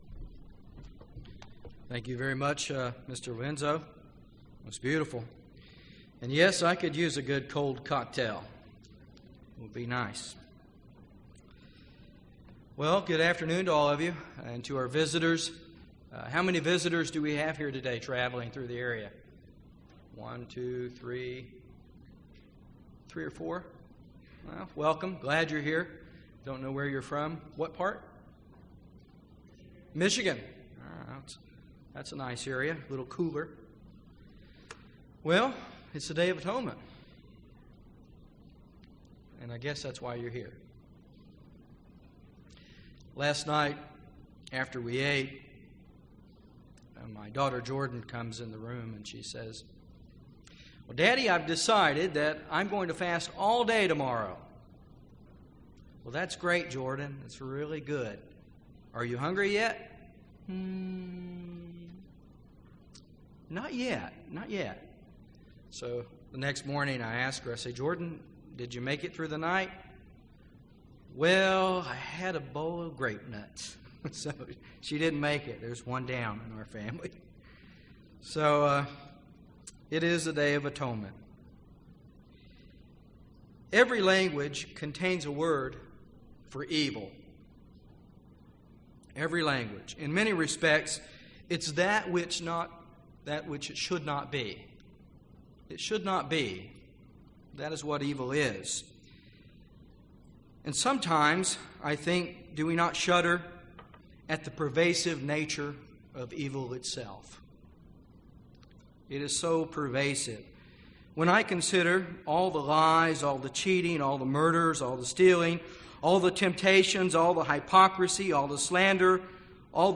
This sermon on the Day of Atonement reviews what the Bible tells us of this evil being and what this day means in relationship to Satan.